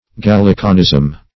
Gallicanism \Gal"li*can*ism\, n.